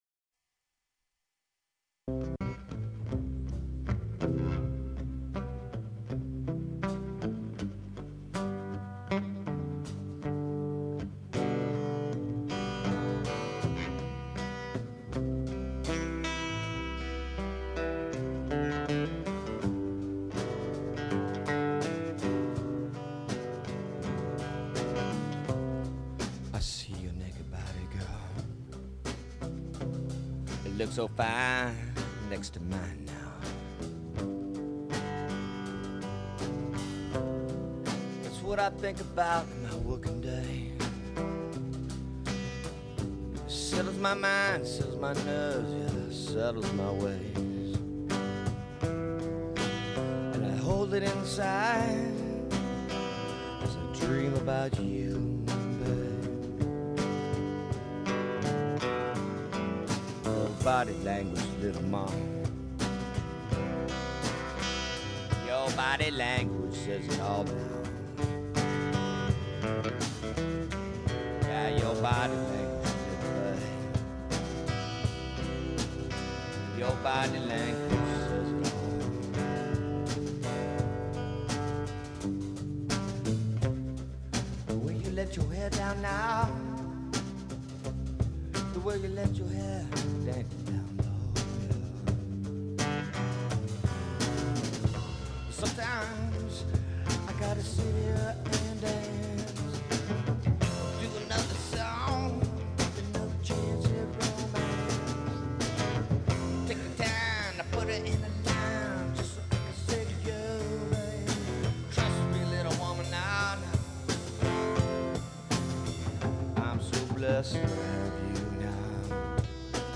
a slow ballad with a ton of Soul
Love song